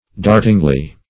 dartingly - definition of dartingly - synonyms, pronunciation, spelling from Free Dictionary Search Result for " dartingly" : The Collaborative International Dictionary of English v.0.48: Dartingly \Dart"ing*ly\, adv. Like a dart; rapidly.